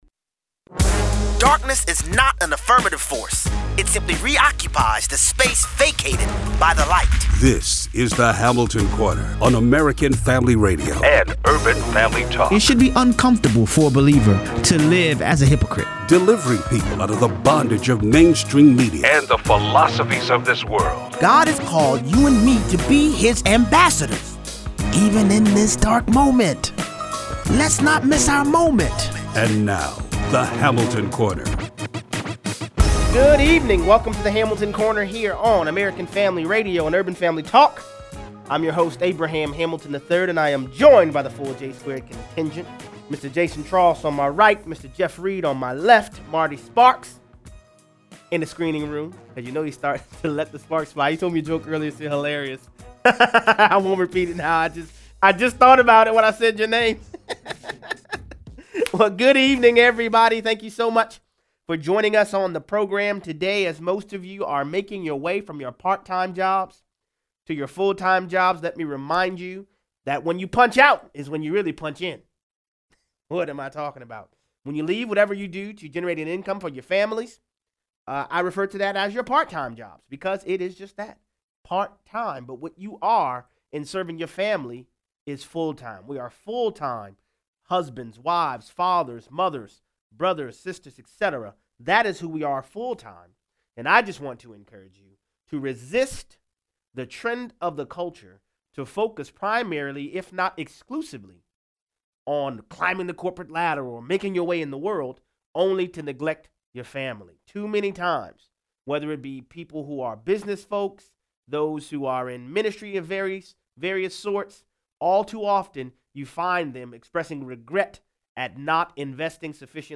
A.G. William Barr defends his actions handling the Mueller report before the U.S. Senate Judiciary Committee. Callers weigh in.